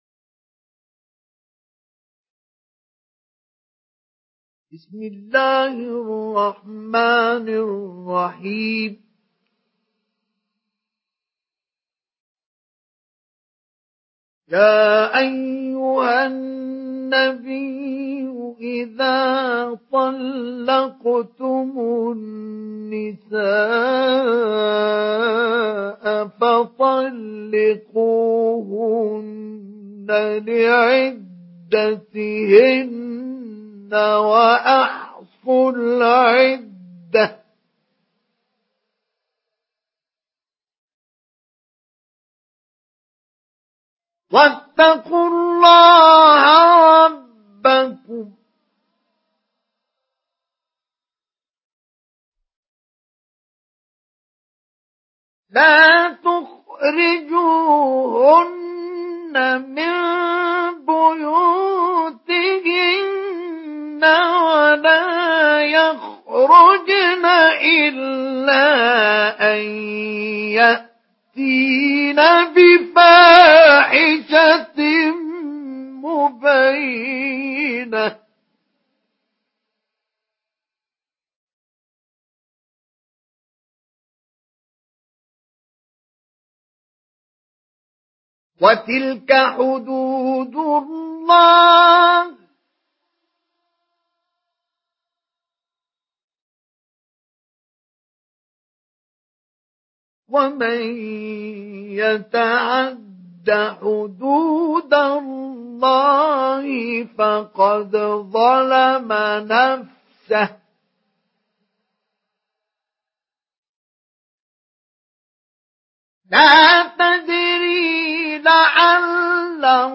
Surah At-Talaq MP3 in the Voice of Mustafa Ismail Mujawwad in Hafs Narration
Surah At-Talaq MP3 by Mustafa Ismail Mujawwad in Hafs An Asim narration.